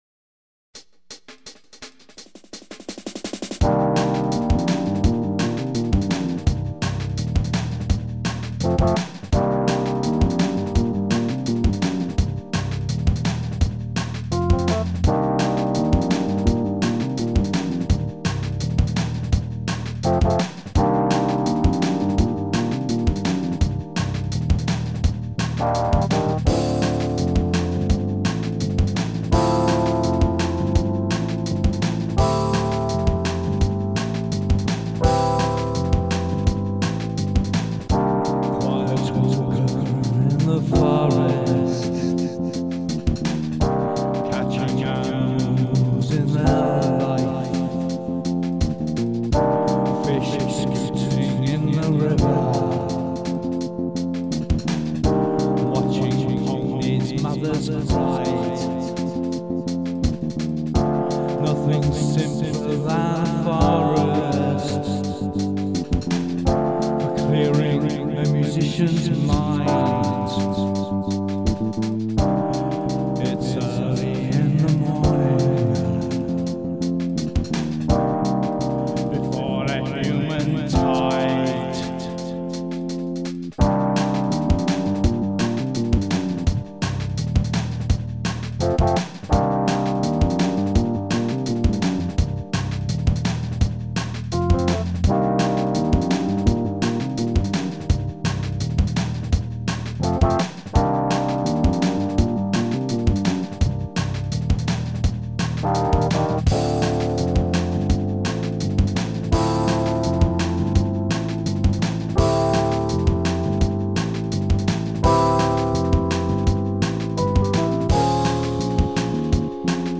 4:46/168bpm